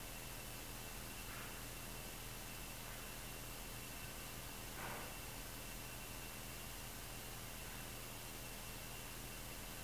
Door Closing
When we captured this EVP, no one was in the house.